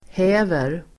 Uttal: [h'ä:ver]